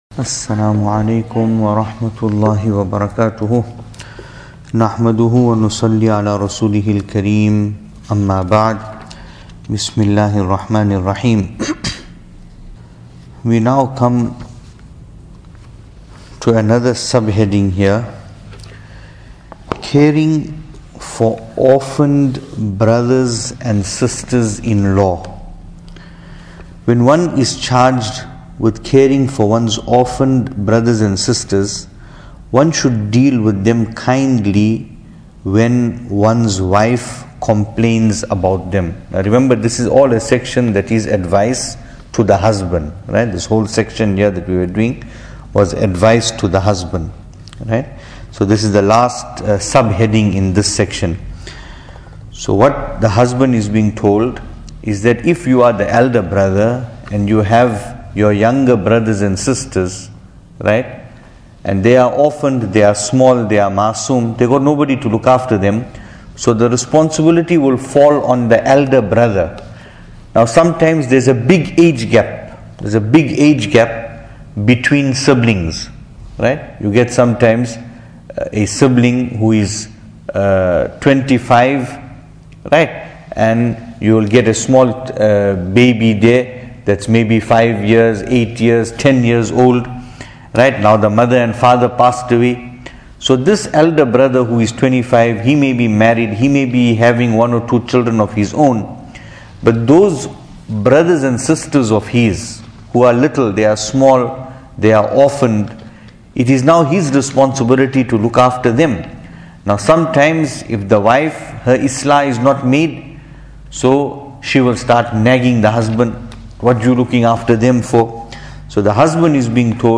Venue: Pietermaritzburg | Series: Tohfa-e-Dulhan